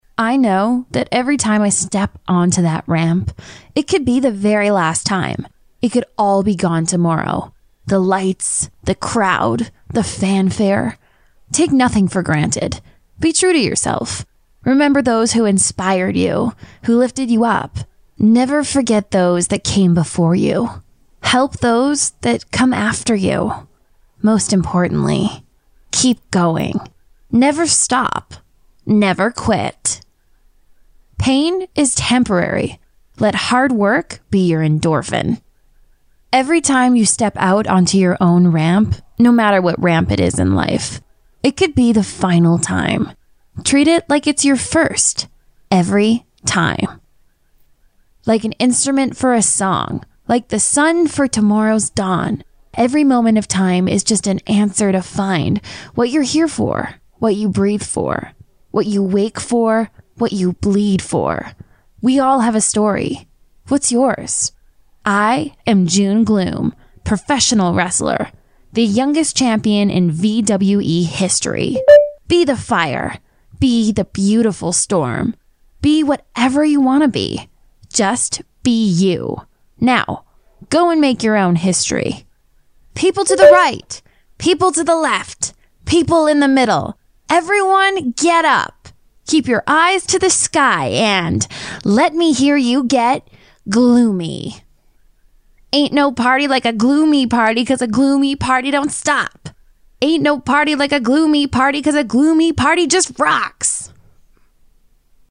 女英111 美式英语 干音sample 低沉|大气浑厚磁性|沉稳|娓娓道来|科技感|积极向上|时尚活力|神秘性感|调性走心|亲切甜美|素人